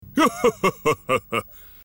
• Качество: высокое
Смех отца свиньи